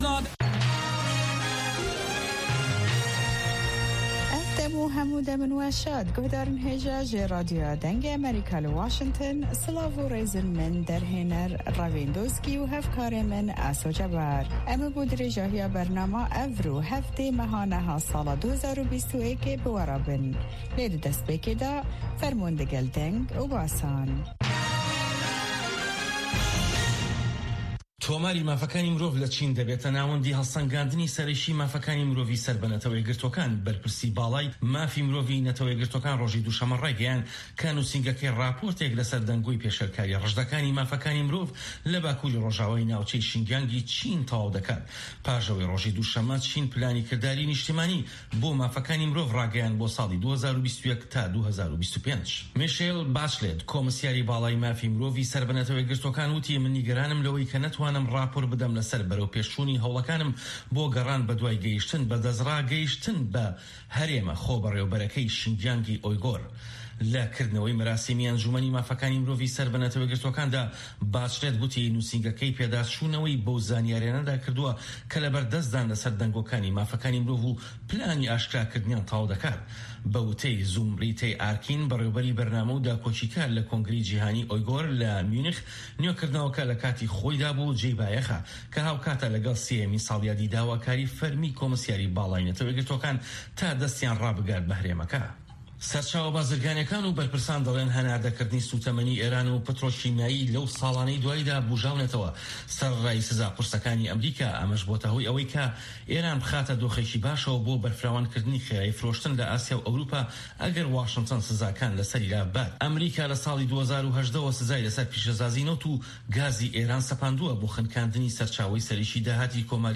هه‌واڵه‌کان، ڕاپۆرت ، وتووێژ